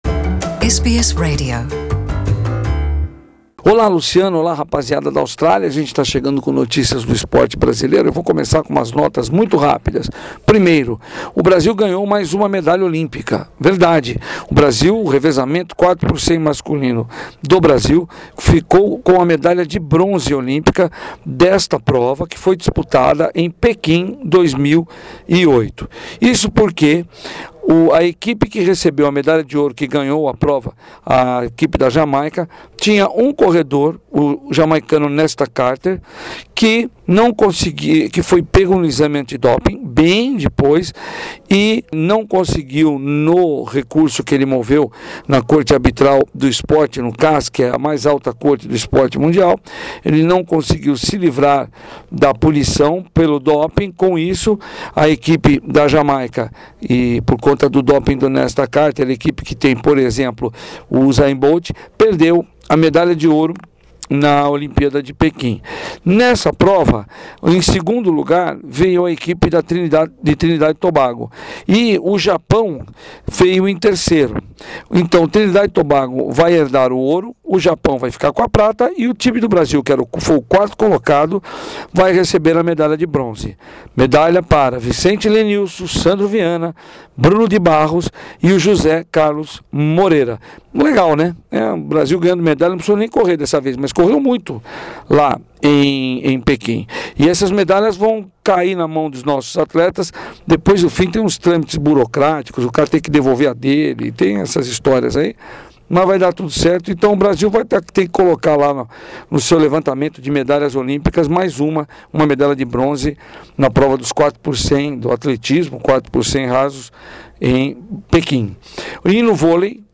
boletim semanal esportivo